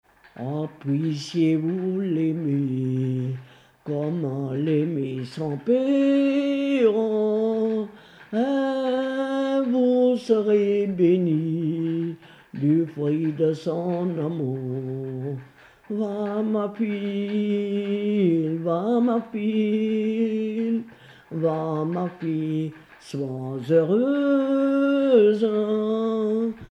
Chanson courte
Pièce musicale inédite